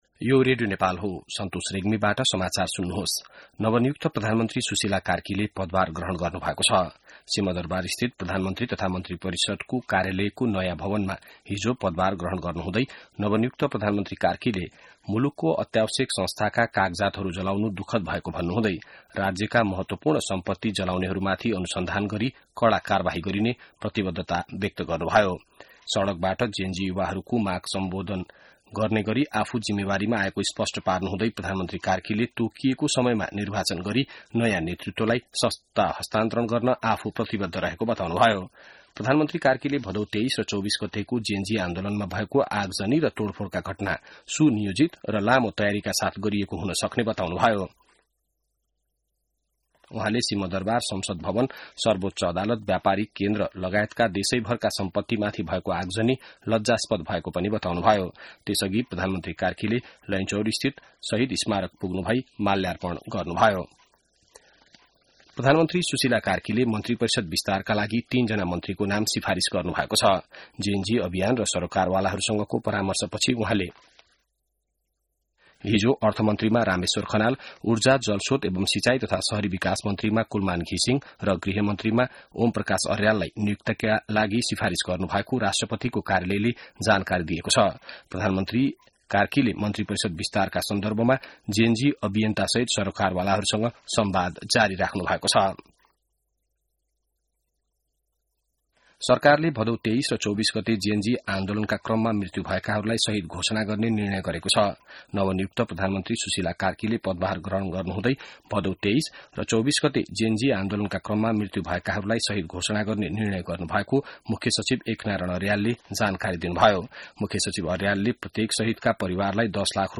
बिहान ६ बजेको नेपाली समाचार : ३० भदौ , २०८२